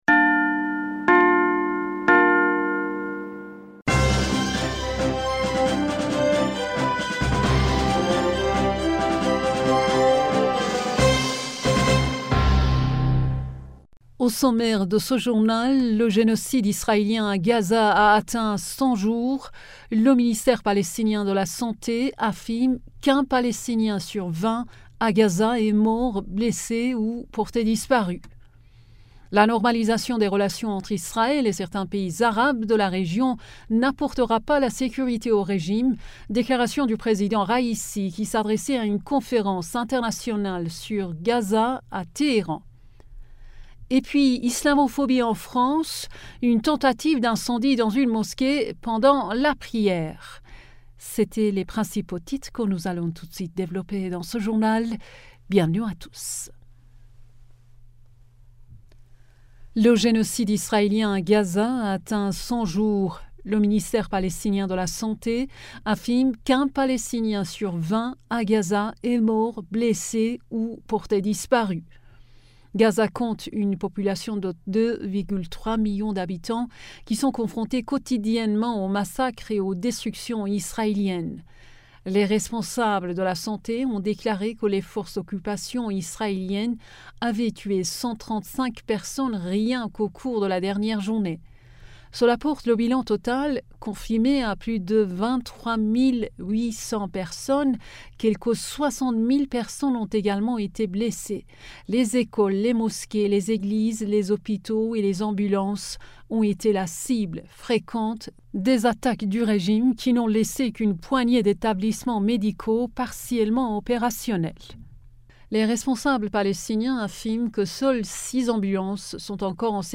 Bulletin d'information du 14 Janvier 2024